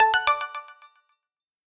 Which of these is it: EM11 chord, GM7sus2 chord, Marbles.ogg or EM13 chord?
Marbles.ogg